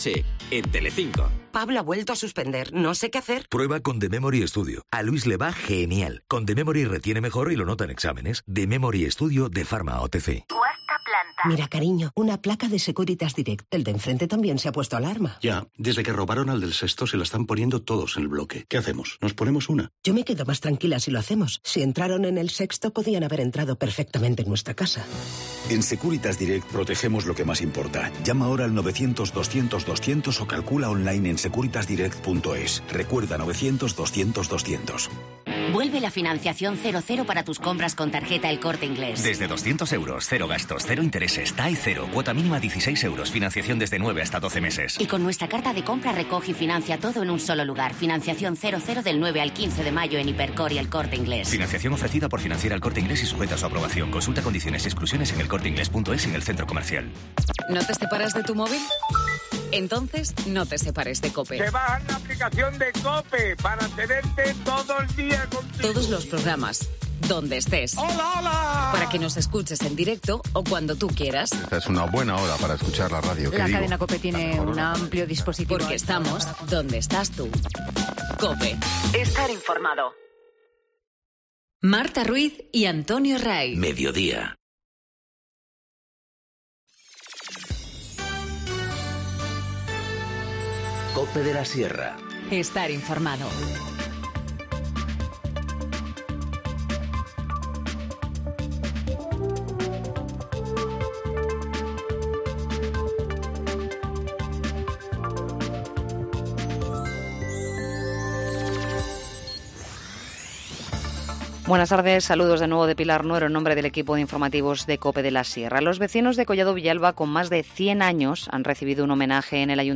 Informativo Mediodía 10 mayo 14:50h